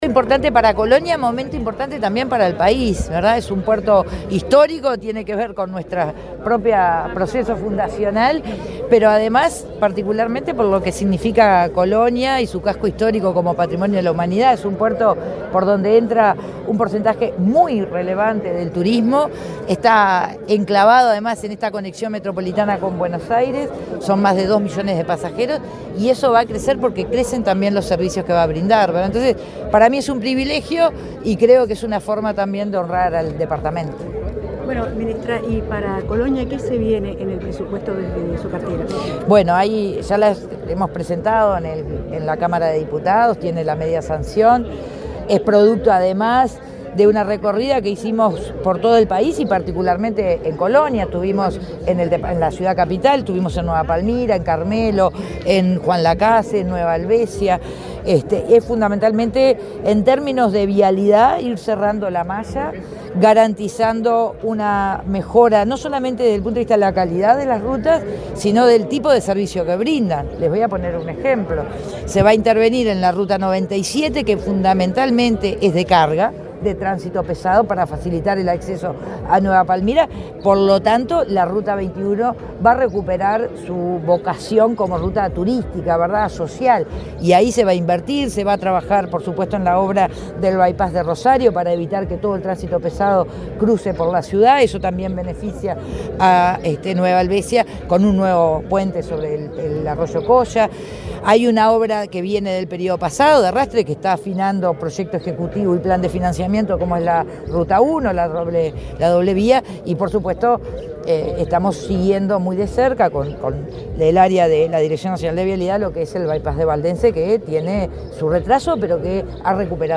Declaraciones de la ministra de Transporte, Lucía Etcheverry
Declaraciones de la ministra de Transporte, Lucía Etcheverry 30/10/2025 Compartir Facebook X Copiar enlace WhatsApp LinkedIn Este jueves 30, la ministra de Transporte, Lucía Etcheverry, dialogó con la prensa, durante su participación en la ceremonia conmemorativa de los 100 años del puerto de Colonia.